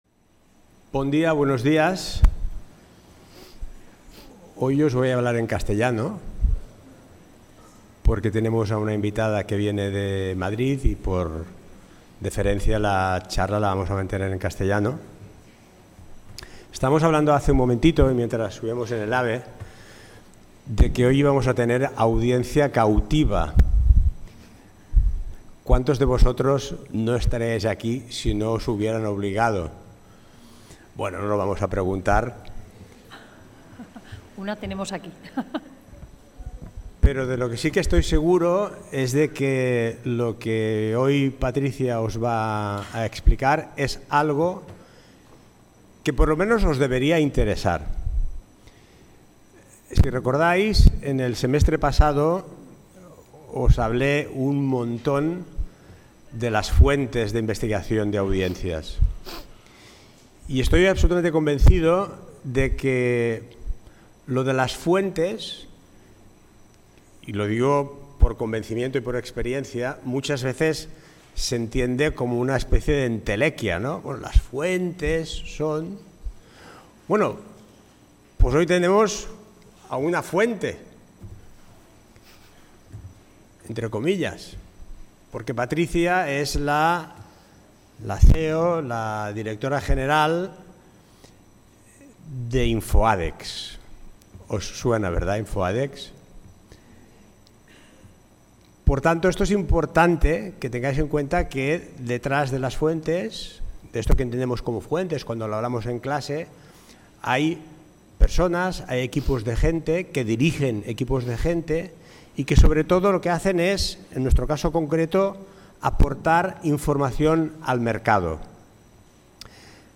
>  La conferència tracta sobre l'impacte econòmic de les campanyes del sector turístic, així com els principals insights vinculats a les inversions publicitàries del sector turístic en el conjunt d'Espanya.